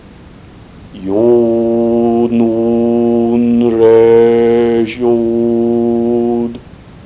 Bu yazıda ayrıca ritüel sırasında zikredilen bazı sözlerin ses dosyaları vardır ve mavi gözüken bu kelimeleri tıklandığında nasıl telaffuz edildikleri sesli bir biçimde gösterilecektir.
Çizerken titreşimsel bir sesle "INRI" zikrediniz.